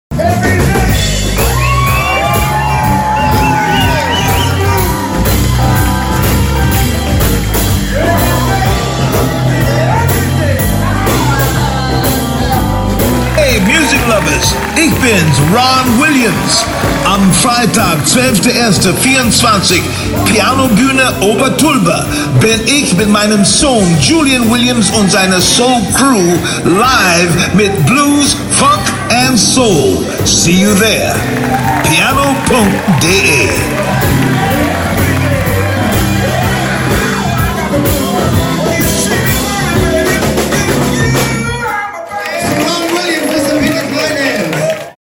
Piano-Jazz, Blues-Konzerte mit international bekannten Künstlern!